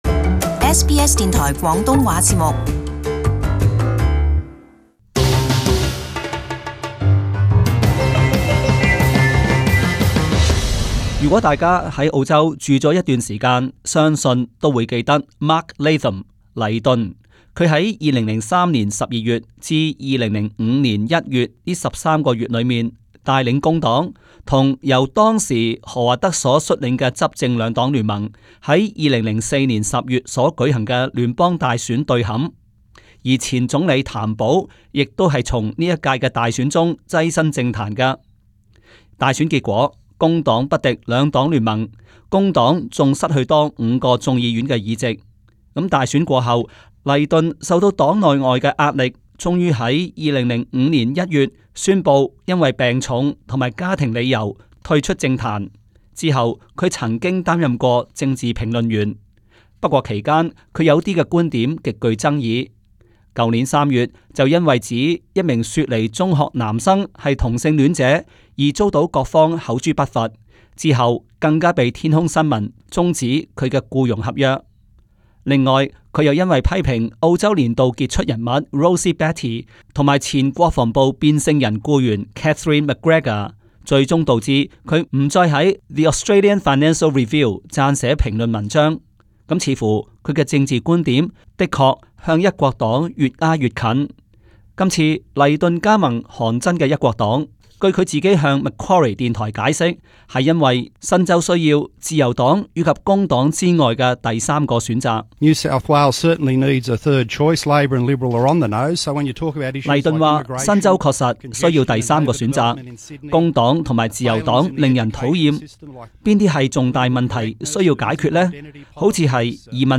【時事報導】 黎頓加入韓珍一國黨參戰新州議會選舉